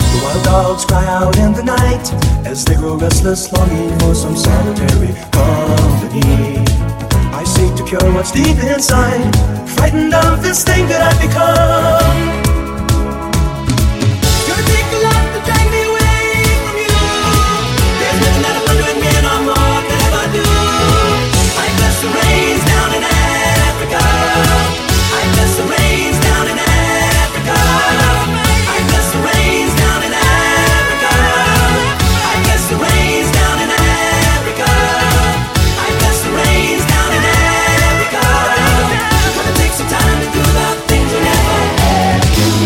Genere: pop, rock, elettronica, successi, anni 80